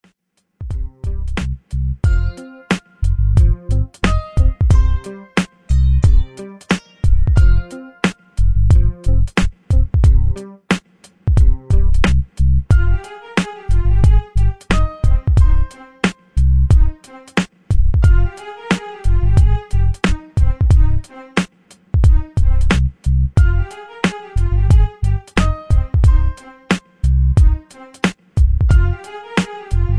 R&B Dance Beat with a swing